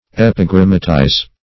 Search Result for " epigrammatize" : The Collaborative International Dictionary of English v.0.48: Epigrammatize \Ep`i*gram"ma*tize\, v. t. [imp.